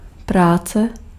Ääntäminen
Ääntäminen : IPA: [ˈpraː.t͡sɛ]